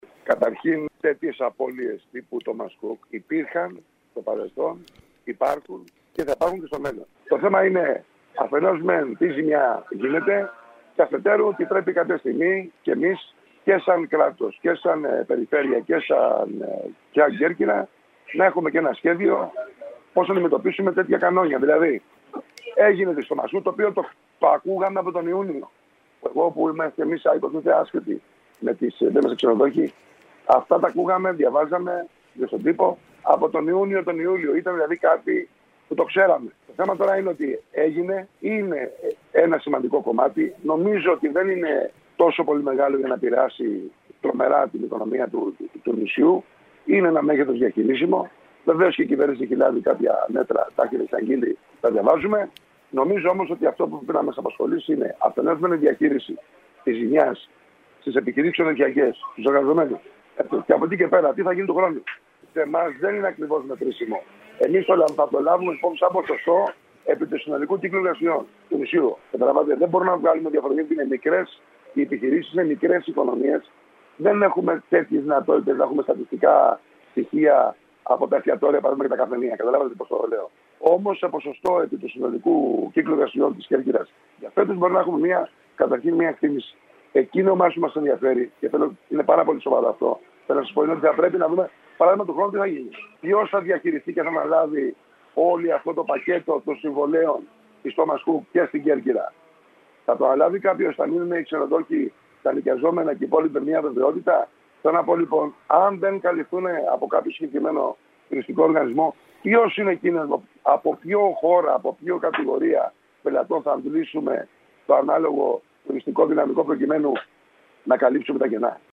μιλώντας σήμερα στην ΕΡΑ ΚΕΡΚΥΡΑΣ